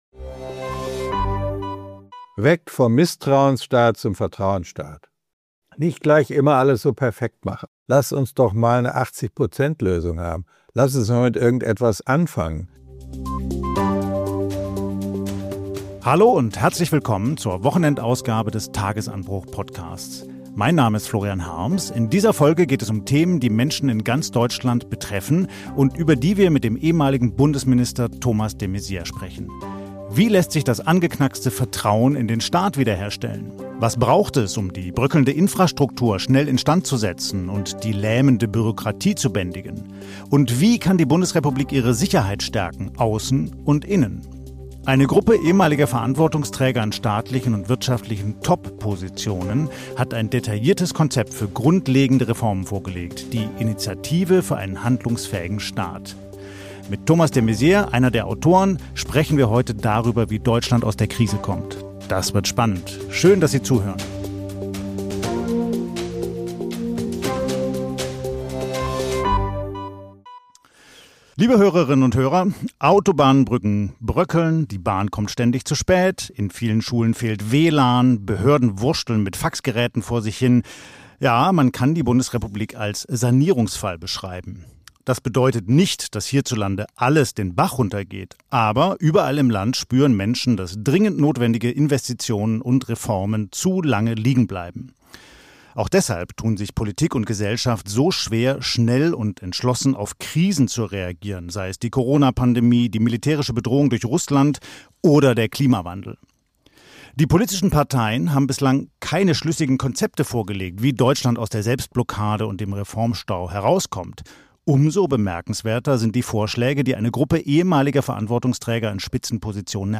De Maizière appelliert im Gespräch an das Vertrauen der Menschen in den Staat und kritisiert den deutschen Perfektionismus.